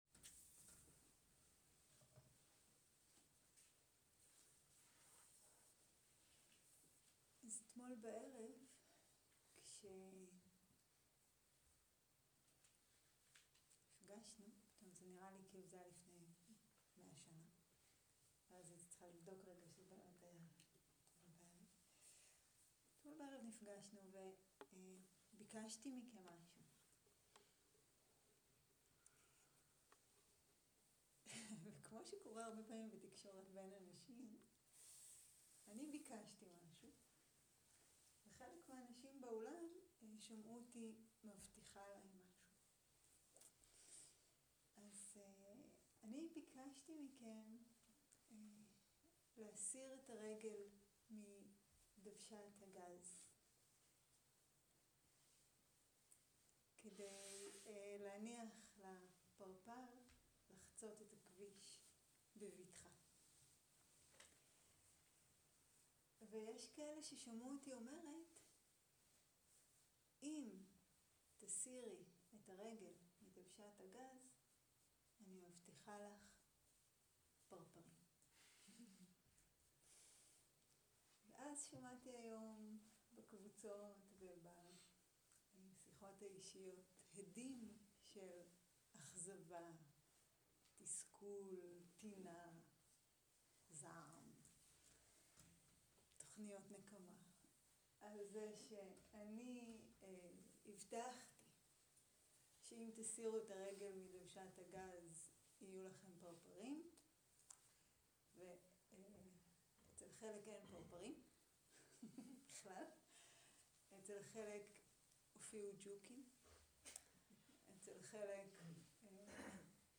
שיחת דהרמה - להוריד את הרגל מדוושת הגז
Dharma type: Dharma Talks